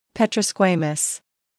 [ˌpetrəu’skweɪməs][ˌпэтроу’скуэймэс]каменисто-чешуйчатый